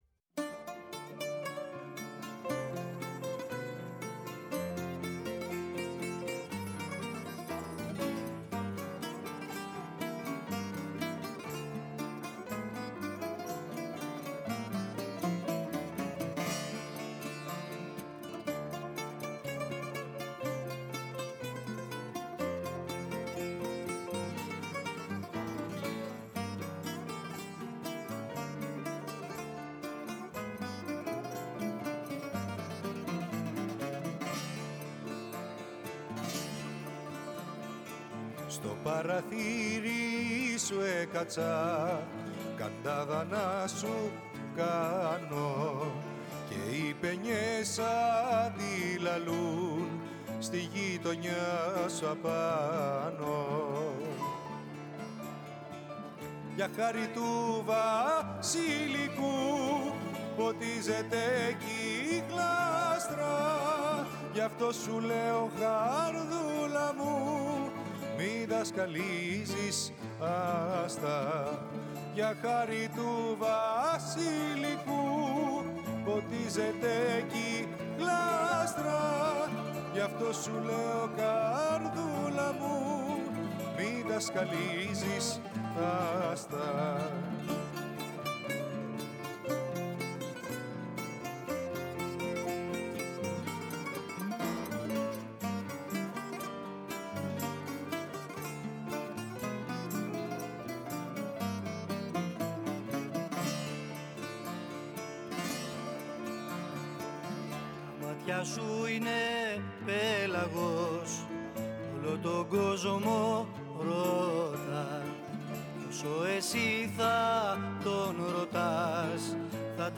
Μπουζούκι
Κρουστά